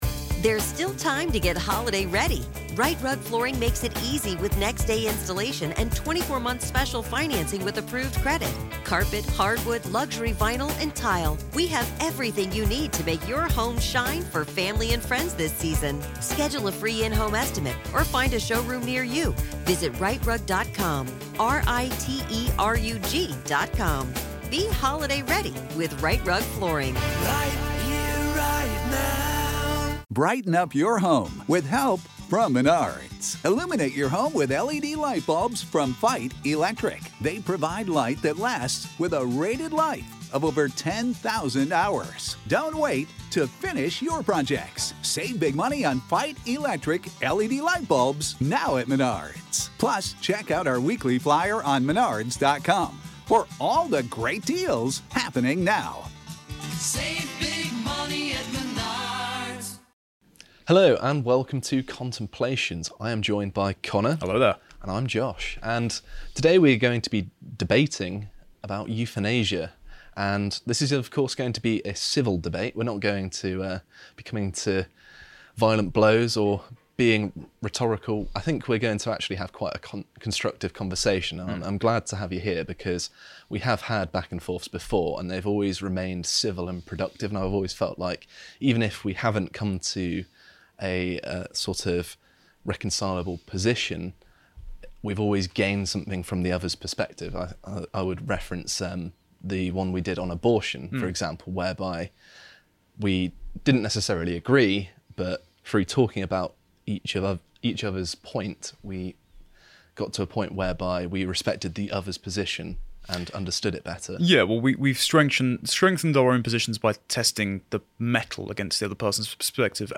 PREVIEW: Contemplations #116 | Debate: Euthanasia